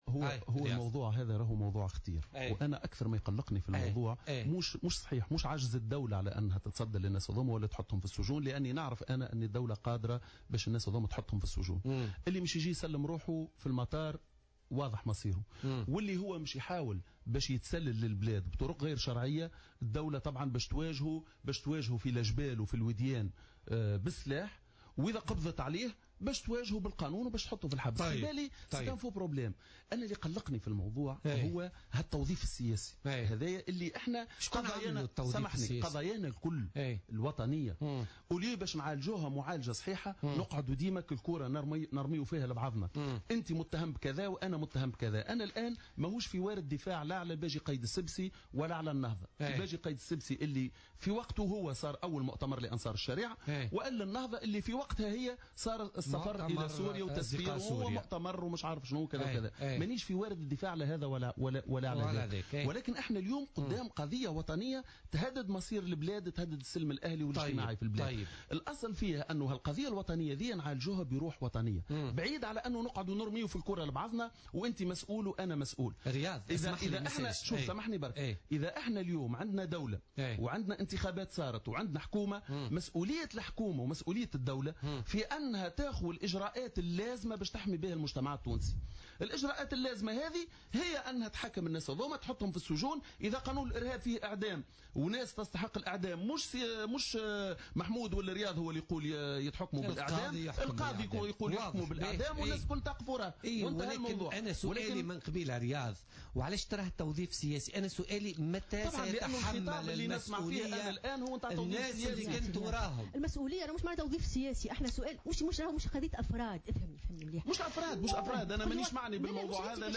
وأضافت يوسف خلال استضافتها اليوم الاثنين 02 جانفي 2017 في برنامج "بوليتكا" أن تونس عاجزة عن إيجاد حل في الوضع الحالي حول مسألة الإرهابيين العائدين، مشيرة إلى أن المؤسسة الأمنية هي مجال الثقة الوحيد الذي يستطيع مواجهة ما سيقع في تونس.